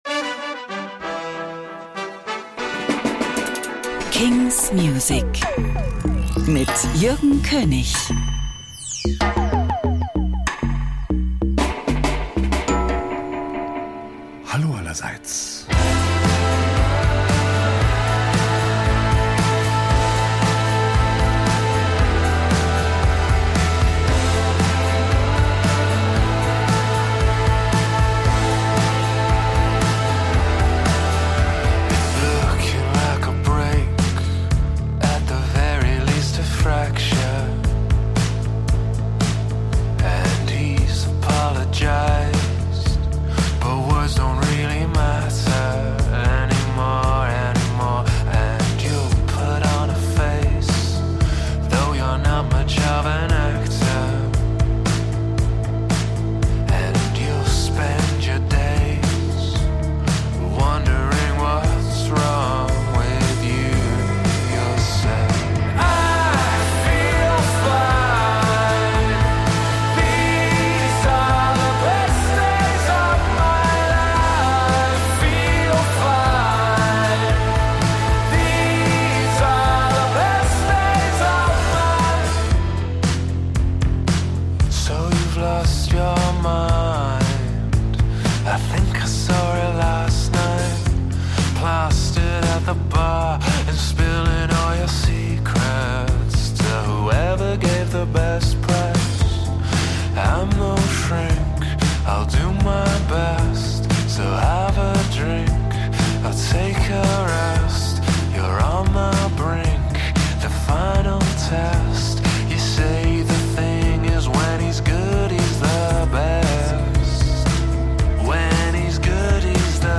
new indie & alternative releases.